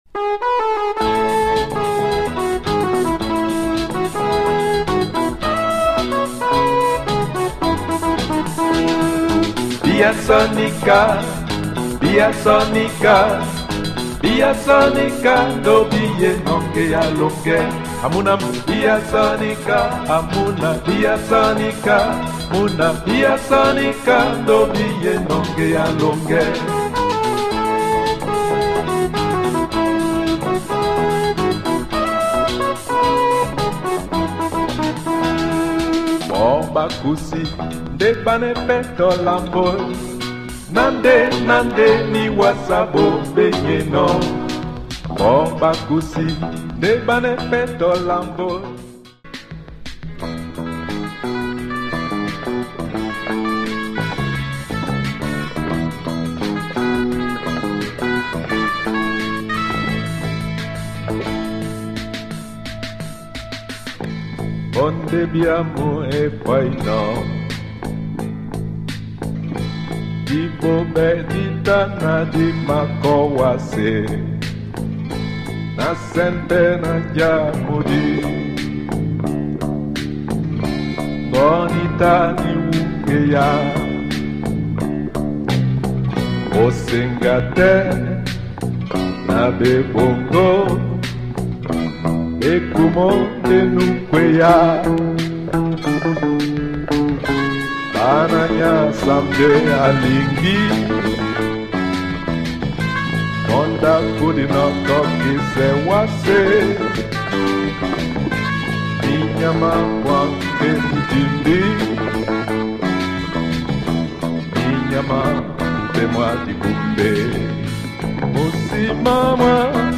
electronic rumba and lo fi makossa !